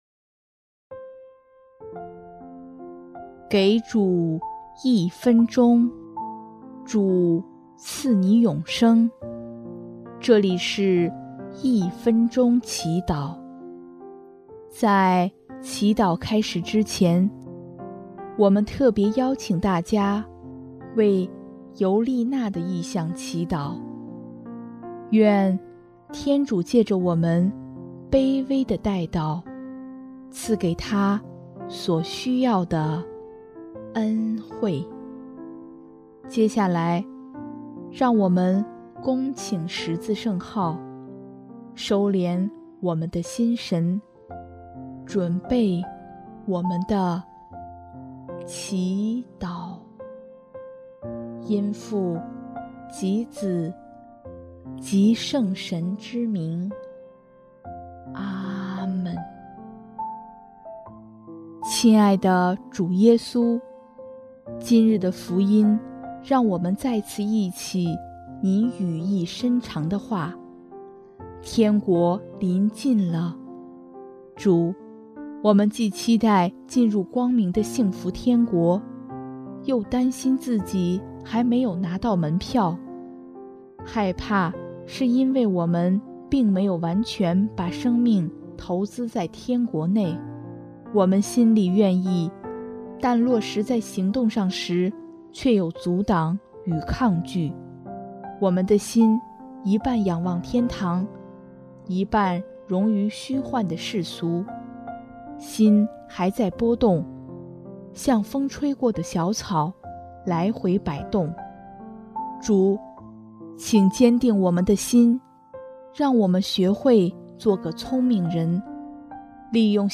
【一分钟祈祷】|6月11日 主，请坚定我们的心